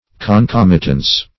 Concomitance \Con*com"i*tance\, Concomitancy \Con*com"i*tan*cy\,